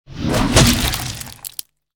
dragonclaw.ogg